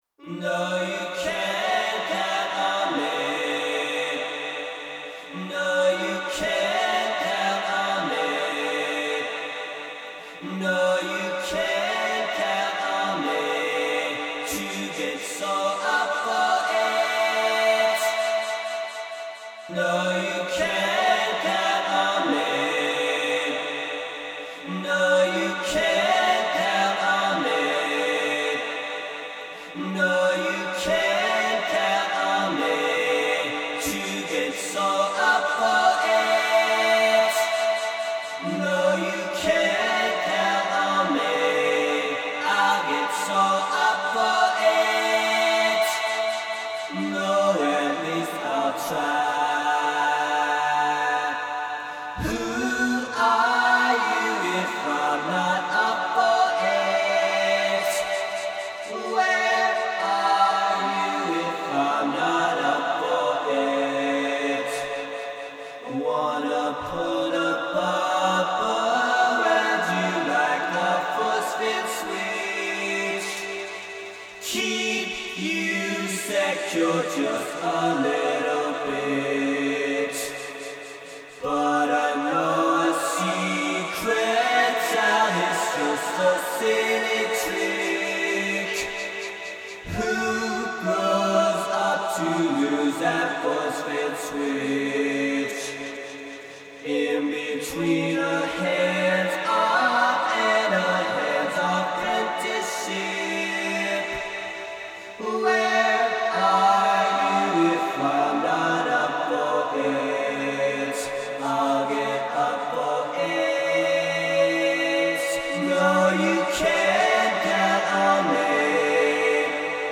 an a capella recording